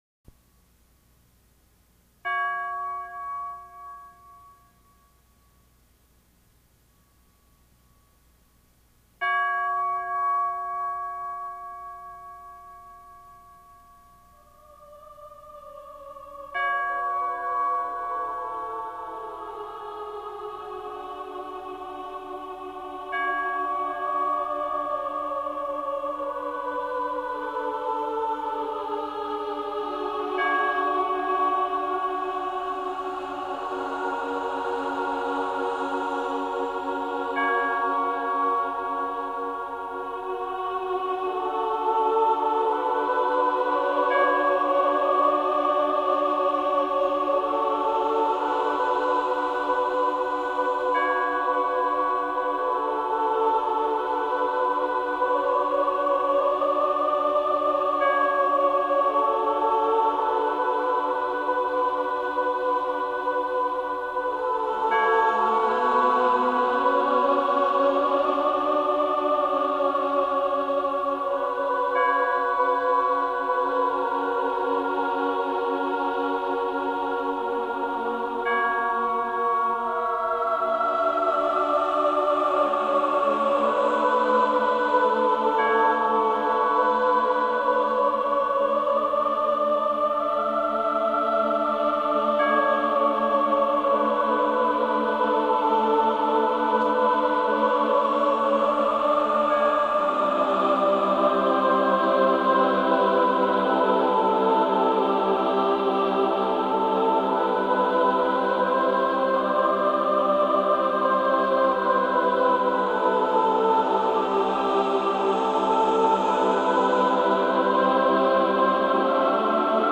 Unwinding Music